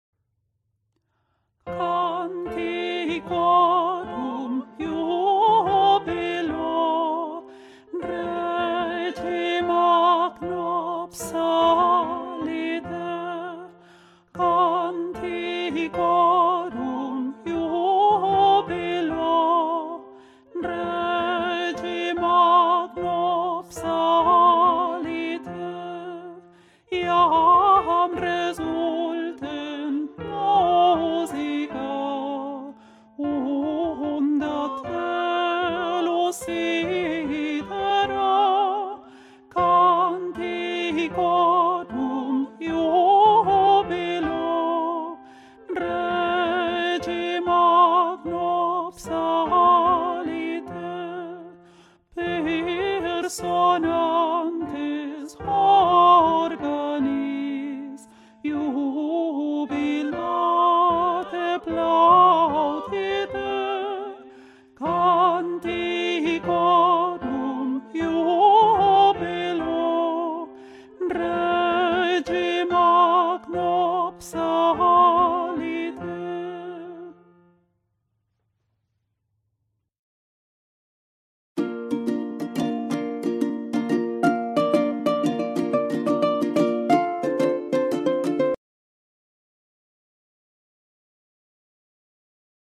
mp3 versions chantées
Alto
Alto Rehearsal F Major Bpm 144